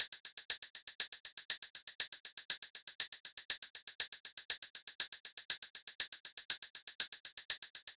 Bp Tamb Loop.wav